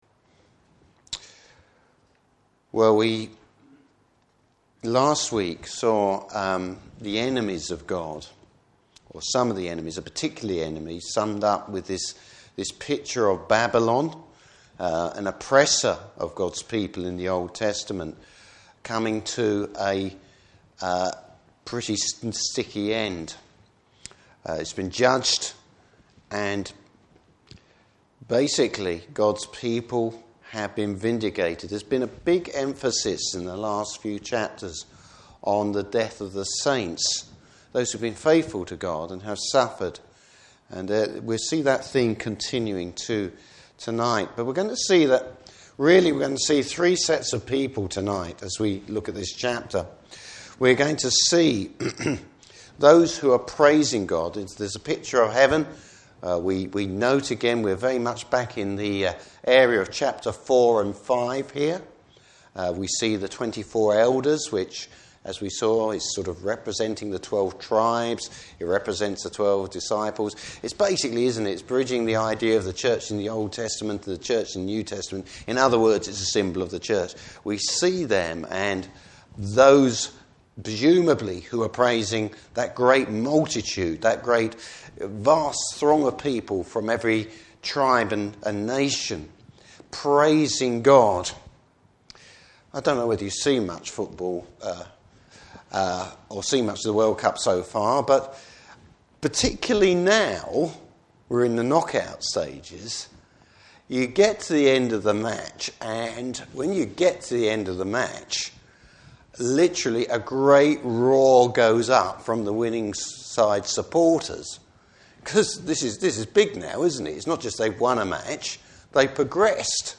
Passage: Revelation 19. Service Type: Evening Service Bible Text: Revelation 19.